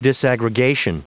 Prononciation du mot disaggregation en anglais (fichier audio)
Prononciation du mot : disaggregation